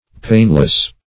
Painless \Pain"less\, a.